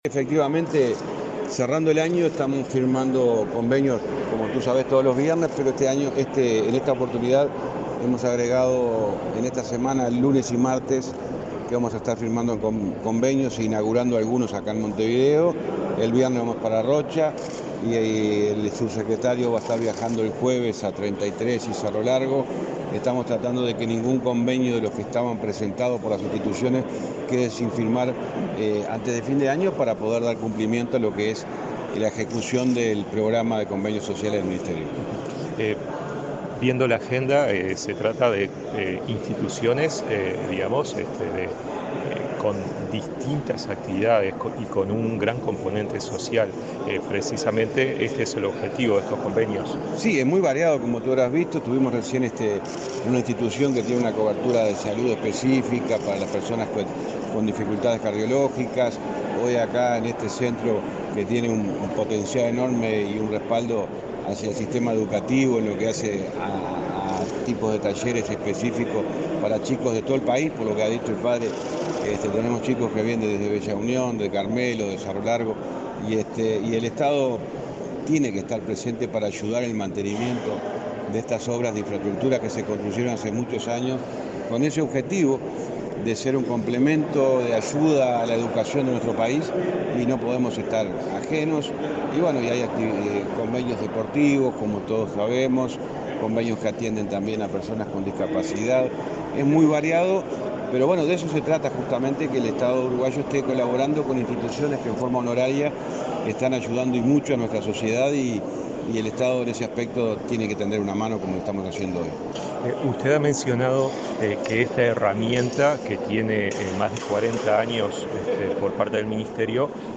Entrevista al ministro de Transporte, José Luis Falero
El ministro de Transporte, José Luis Falero, dialogó con Comunicación Presidencial durante su recorrida por Montevideo, donde firmó convenios sociales